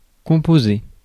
Ääntäminen
IPA: [kɔ̃.po.ze]